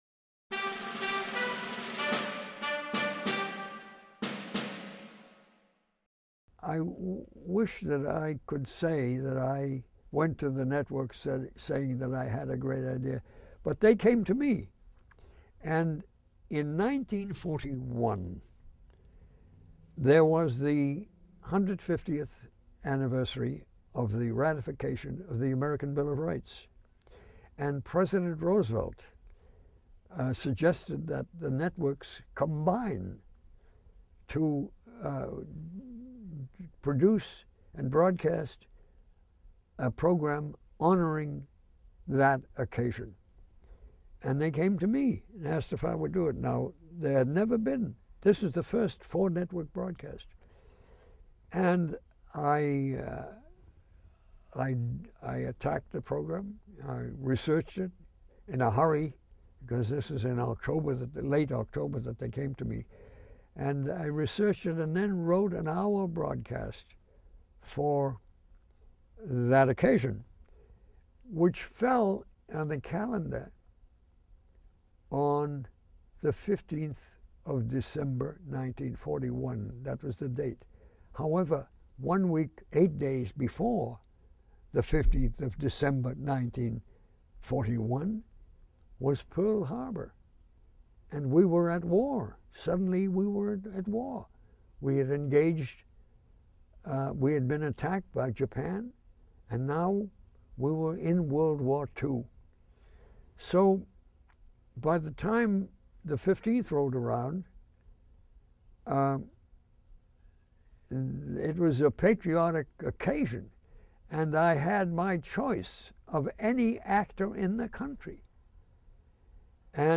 In an interview in 2004, Norman Corwin recalled the program and the events that led up to its airing.